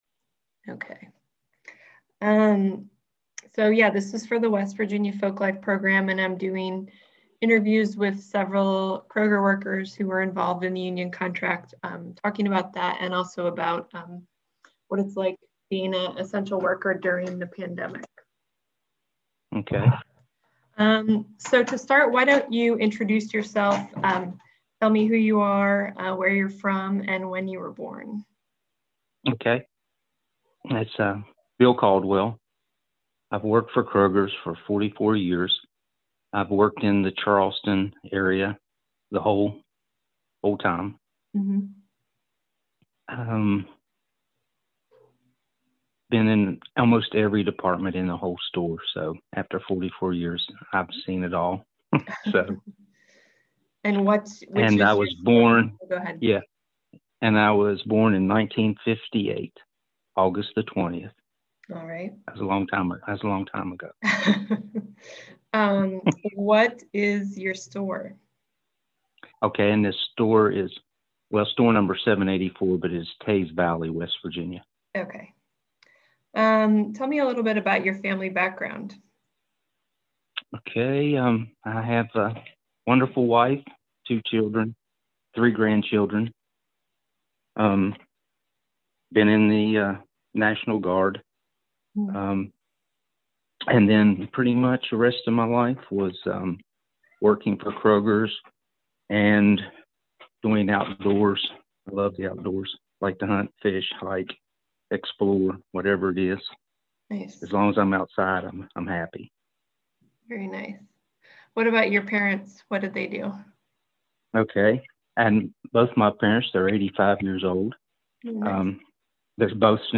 In this interview he speaks about recent contract negotiations with Kroger, union actions hes been involved in in the past, and his experience as a grocery store essential worker during the COVID-19 pandemic. This interview is part of a collection of interviews with UFCW member Kroger workers conducted remotely during the COVID-19 pandemic.